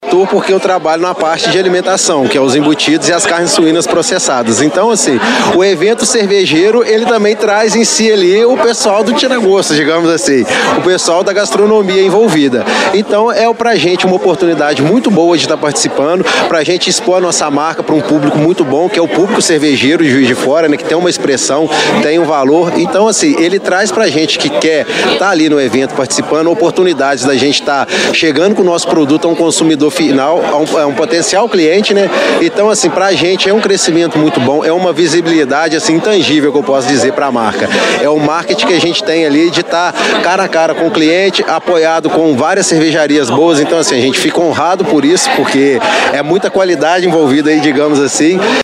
Em um evento na noite de quarta-feira (9) no Seminário da Floresta, representantes dos setores envolvidos falaram sobre a importância da produção cervejeira na cidade, as novidades e a expectativa para este ano.